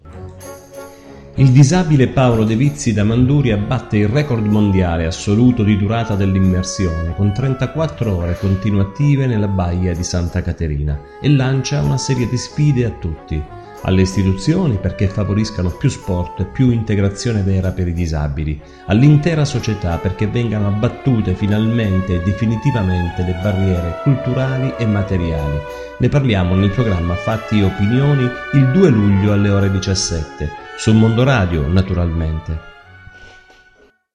Documenti allegati: Ecco il promo della puntata